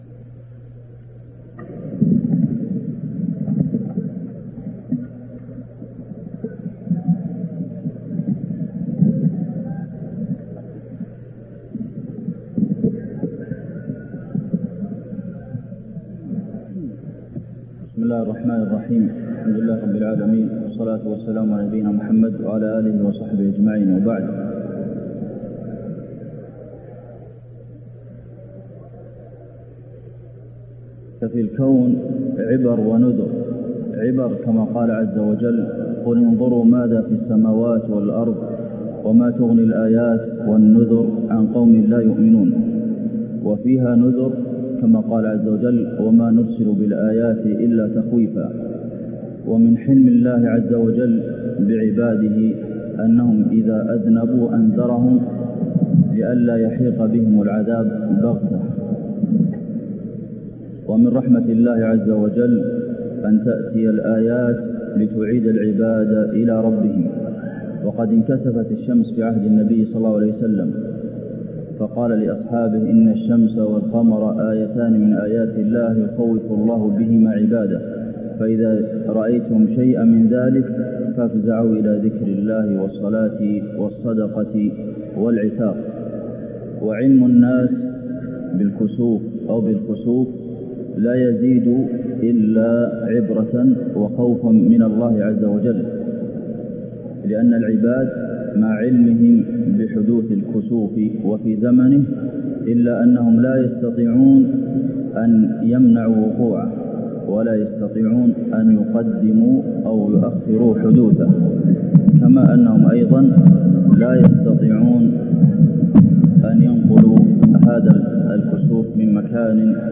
خطبة الكسوف ٢٩ شعبان ١٤٢٦هـ > الكسوف 🕌 > المزيد - تلاوات الحرمين